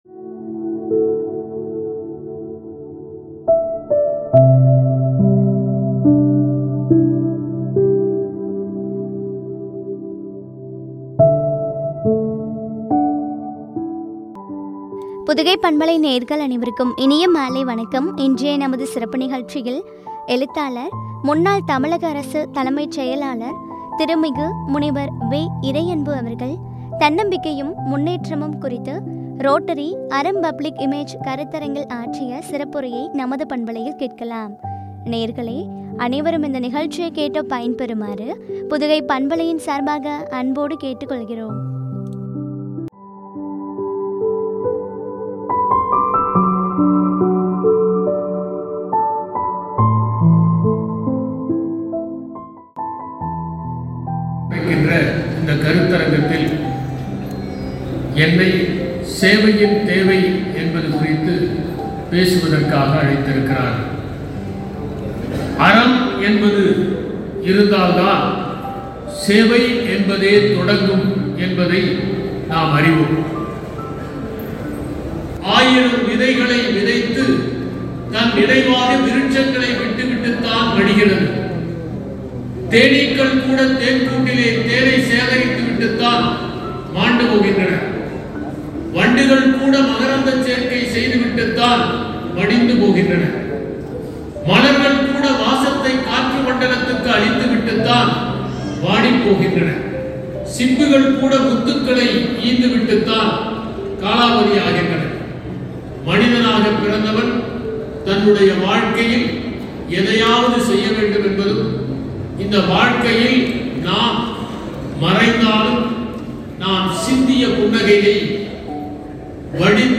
எழுத்தாளர், முன்னாள் தமிழக அரசு தலைமைச் செயலாளர், திருமிகு முனைவர் வெ. இறையன்பு அவர்கள், ” தன்னம்பிக்கையும் முன்னேற்றமும்” ( ரோட்டரி அறம் பப்ளிக் இமேஜ் கருத்தரங்கில் ஆற்றிய சிறப்புரை), குறித்து வழங்கிய உரையாடல்.